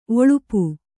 ♪ oḷupu